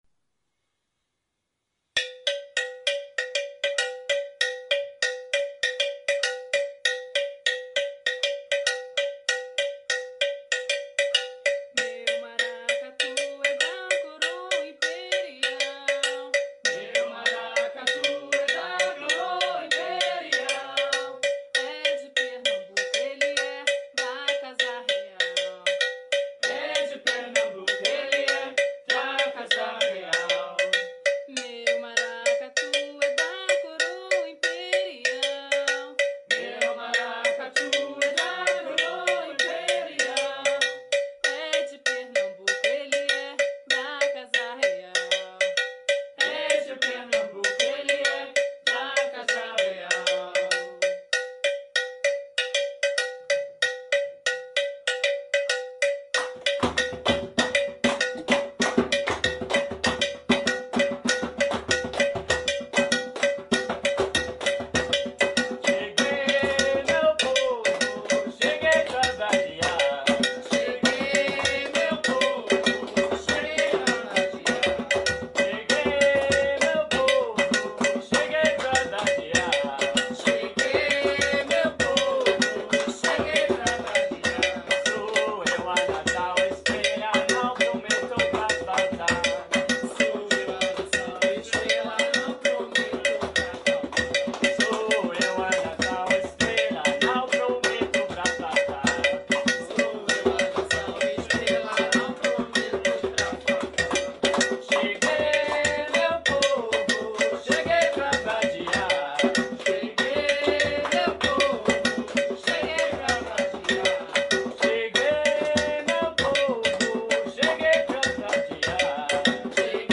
Maracatu
11 Maracatu.mp3